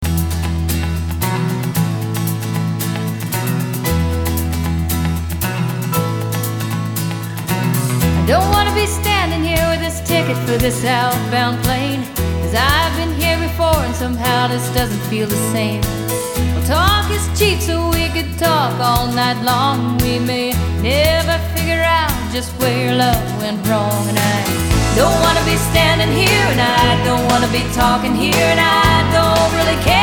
I love the way that song starts with the announcement!